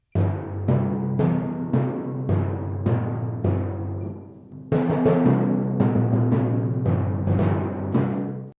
Звучание ударного музыкального инструмента литавры в mp3 формате
Ниже звуки ударов литавры с разной частотой и силой, которые вы можете послушать онлайн и загрузить на телефон, планшет или компьютер бесплатно.
1. Звук литавры